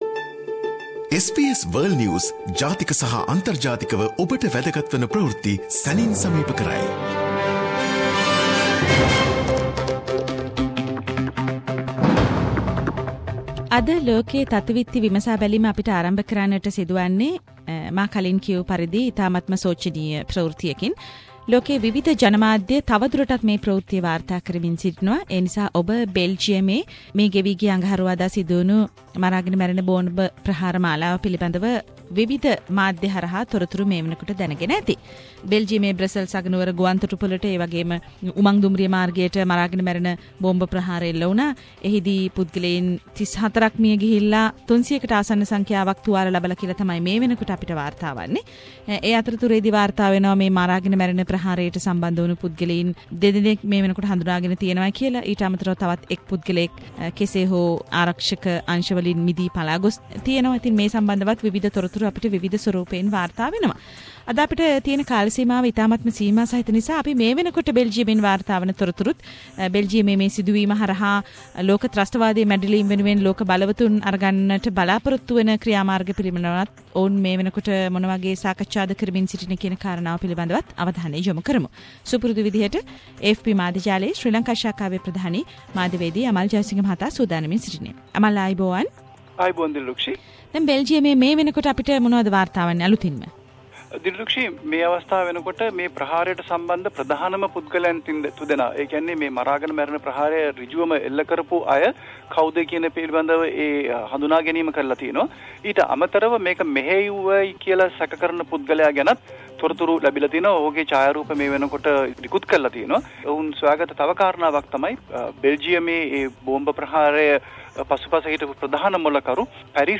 Weekly world news wrap